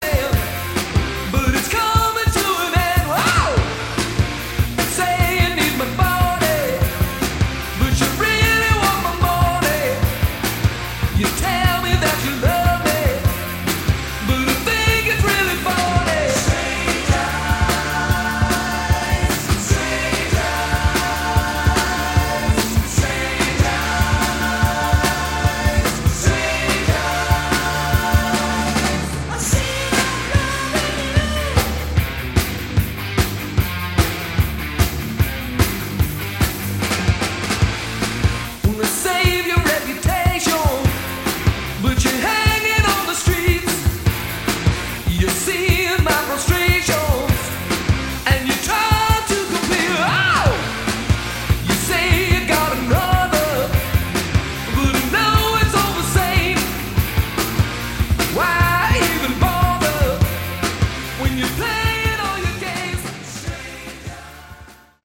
Category: Hard Rock
All Guitars
Drums, Percussion
Vocals, Screams
Bass, Keyboards, Vocals